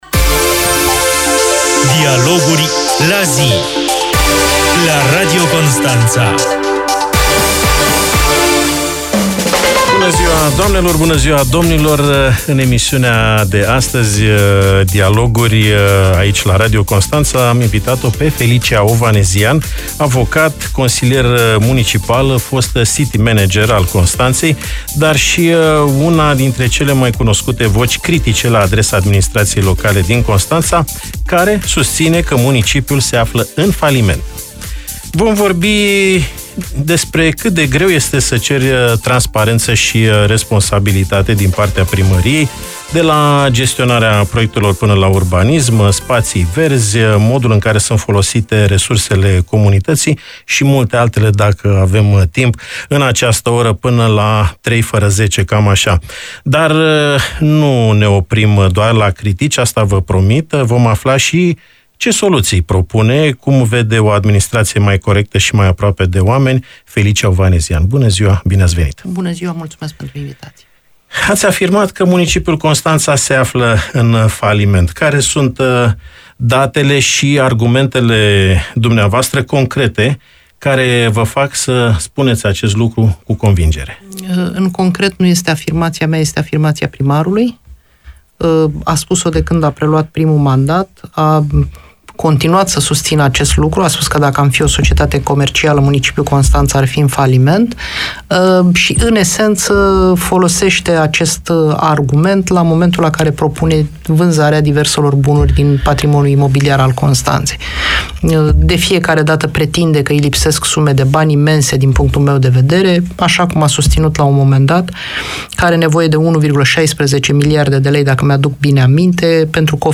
Consilierul local independent Felicia Ovanesian, la Radio Constanța - Știri Constanța - Radio Constanța - Știri Tulcea